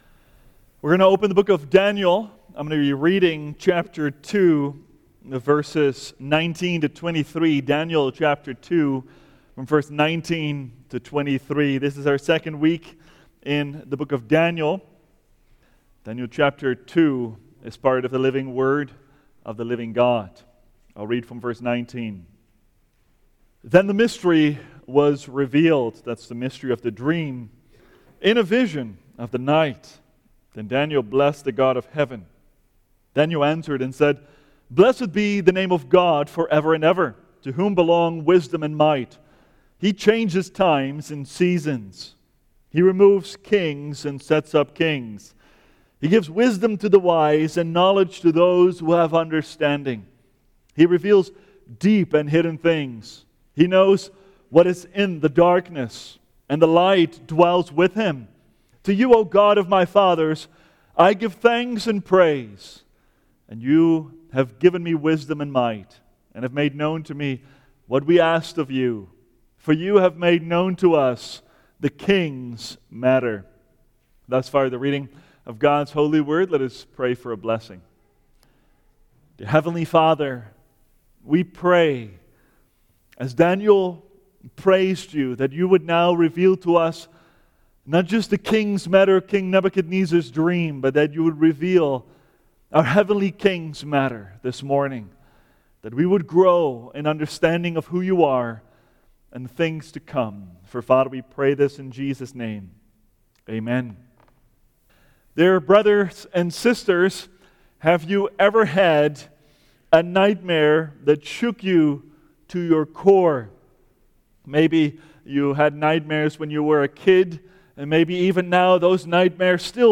The Terrifying Statue of Nebuchadnezzar’s Dream – Seventh Reformed Church